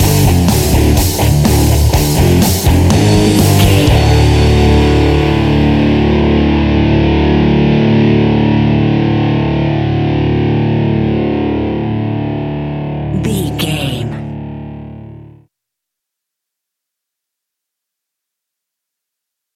Haunted Metal Rock Music Stinger.
Ionian/Major
energetic
driving
heavy
aggressive
electric guitar
bass guitar
drums
electric organ
hard rock
heavy metal
distortion
distorted guitars
hammond organ